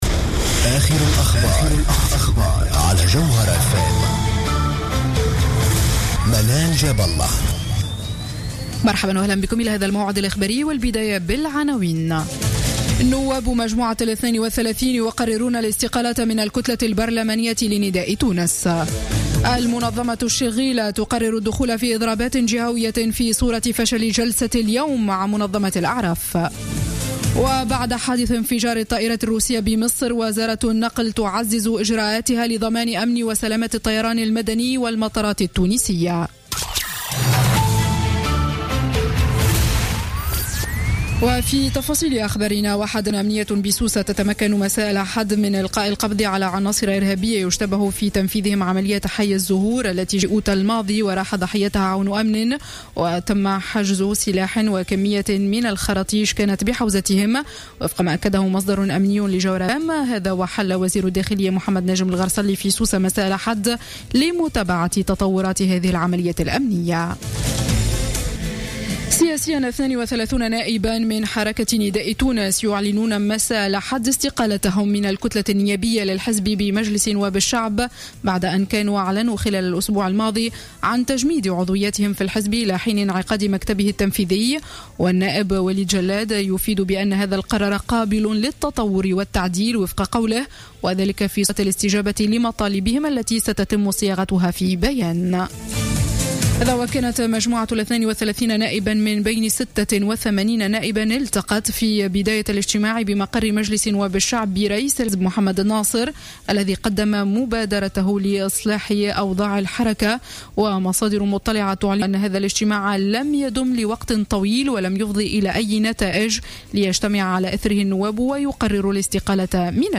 نشرة أخبار منتصف الليل ليوم الإثنين 9 نوفمبر 2015